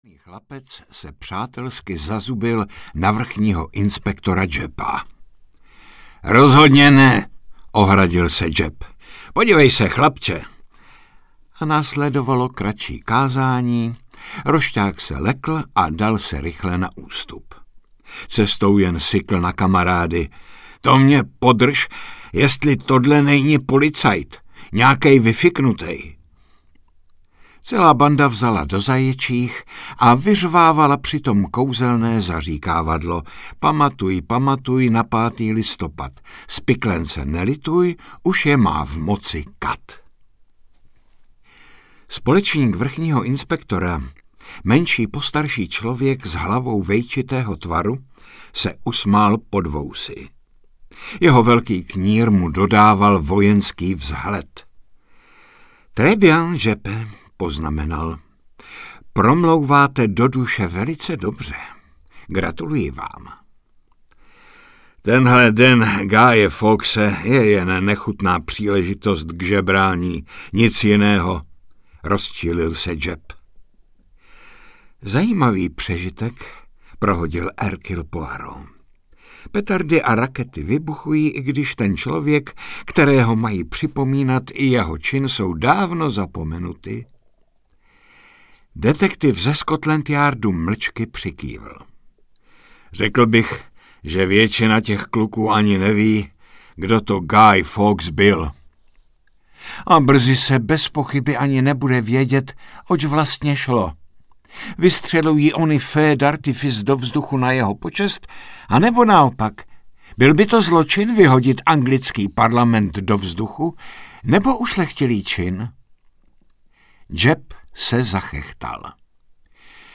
Podzimní děsy audiokniha
Ukázka z knihy